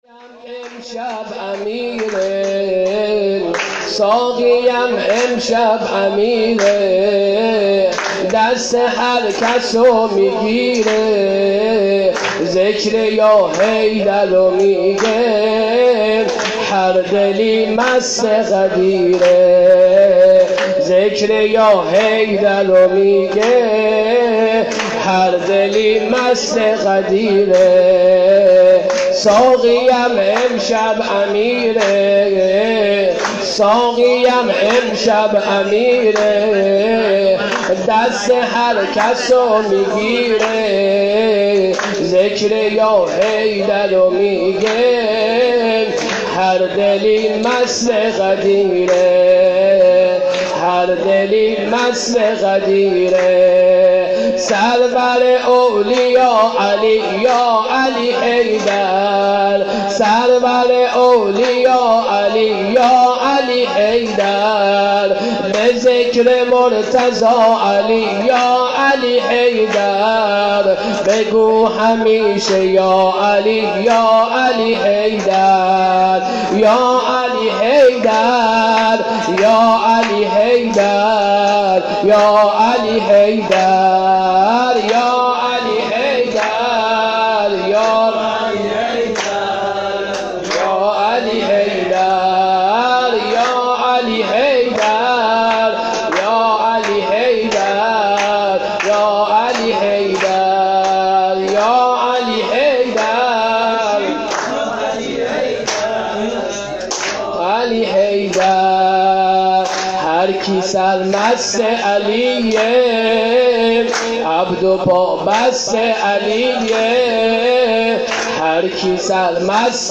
عید غدیر96